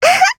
Taily-Vox_Jump_jp_c.wav